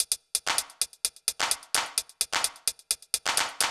TEC Beat - Mix 7.wav